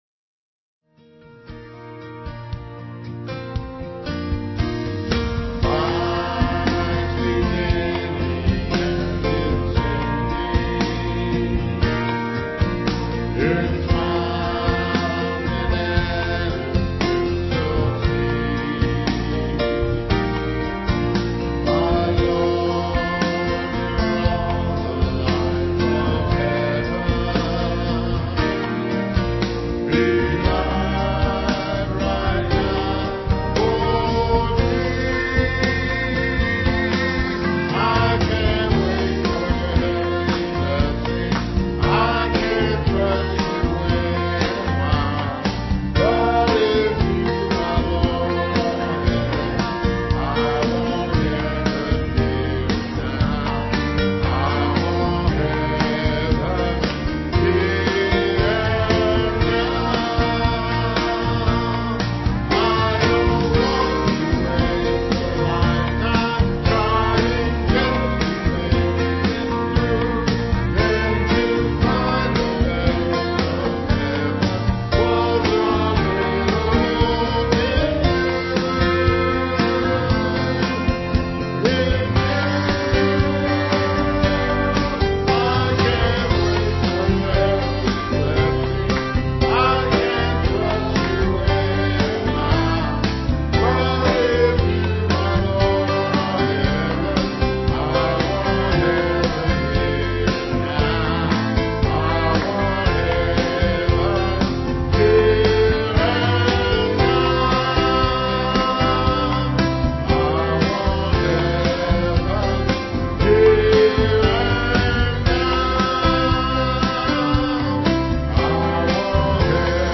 piano
digital piano.